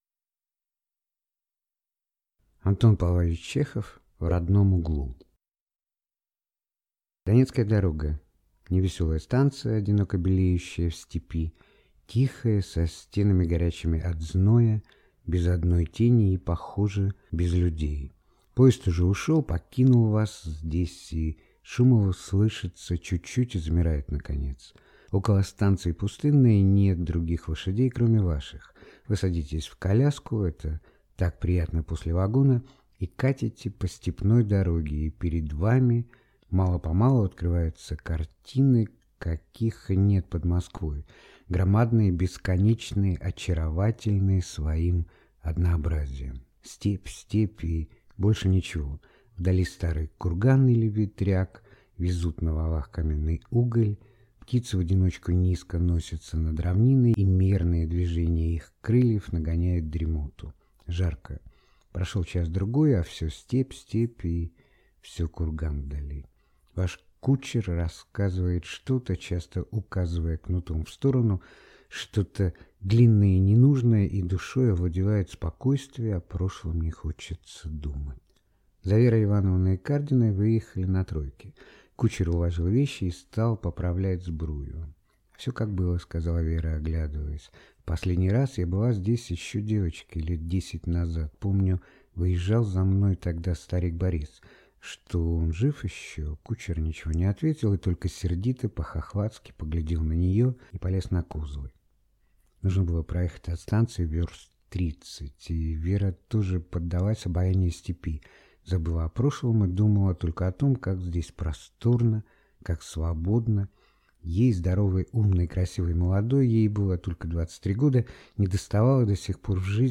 Аудиокнига В родном углу | Библиотека аудиокниг